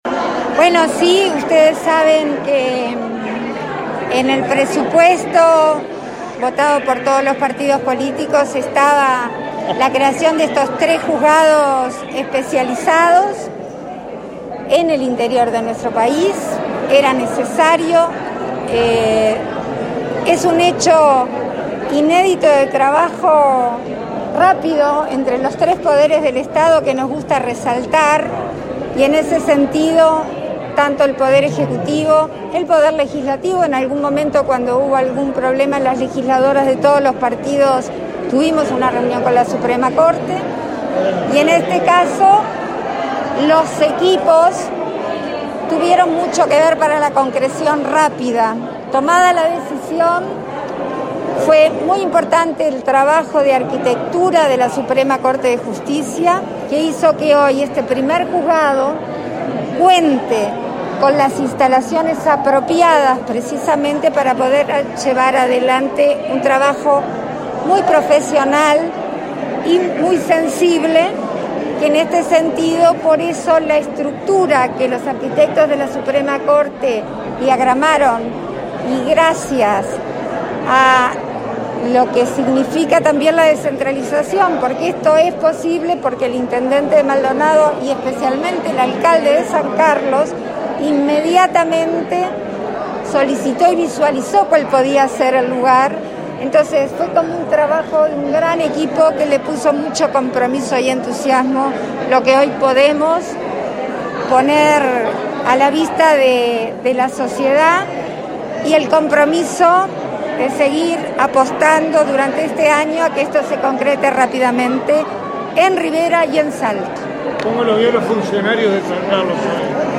Declaraciones de la presidenta en ejercicio, Beatriz Argimón, a la prensa
Declaraciones de la presidenta en ejercicio, Beatriz Argimón, a la prensa 22/02/2022 Compartir Facebook X Copiar enlace WhatsApp LinkedIn La presidenta en ejercicio, Beatriz Argimón, participó de la inauguración de dos juzgados especializados en violencia basada en género, doméstica y sexual, en San Carlos, Maldonado, y, luego, dialogó con la prensa.